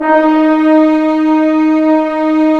Index of /90_sSampleCDs/Roland - Brass, Strings, Hits and Combos/ORC_Orc.Unison p/ORC_Orc.Unison p